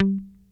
F3 4 F.BASS.wav